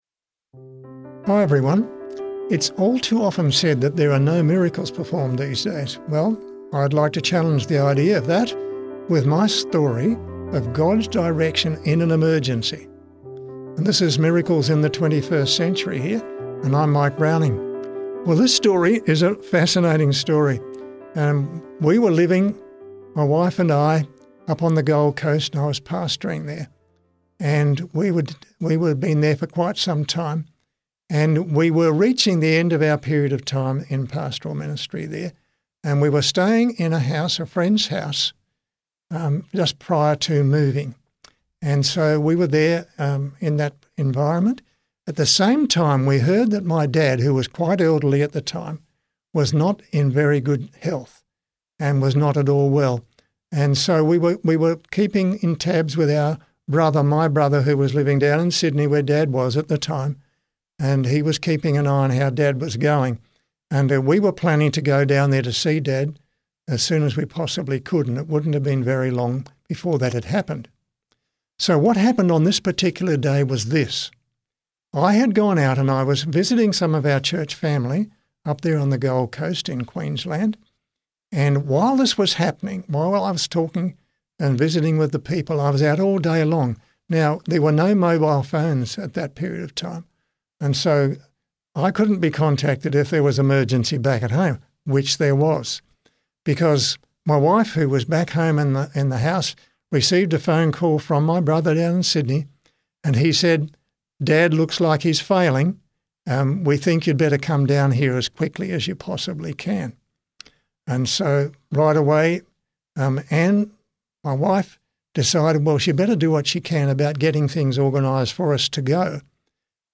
• Perfect timing miracle testimony